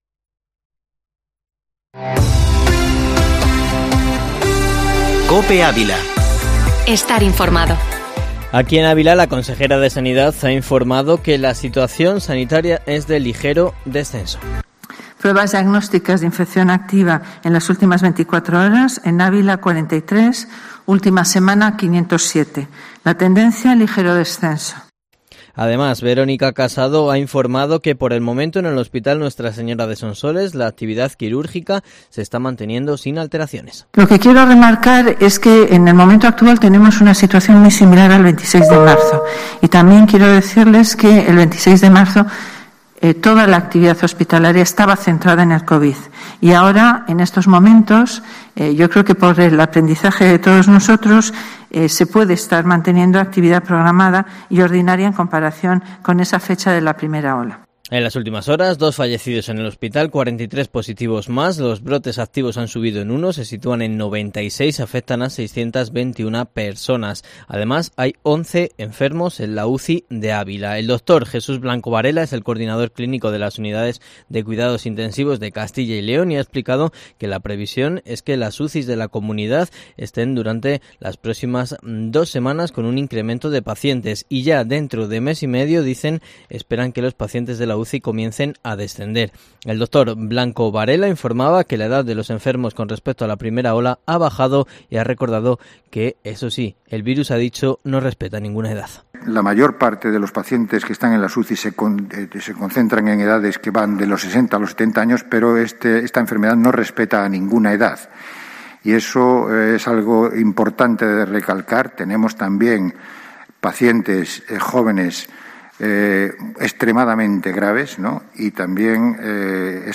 Informativo matinal Herrera en COPE Ávila 11/11/2020